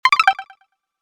ob-sfx-game-start.fc0f30976f8cb58ac970.mp3